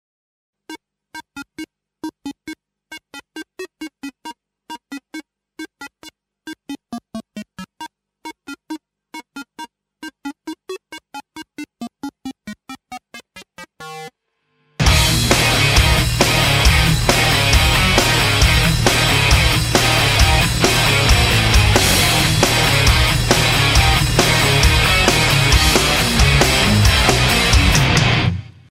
• Качество: 128, Stereo
гитара
громкие
без слов
электрогитара
рок
пауэр-метал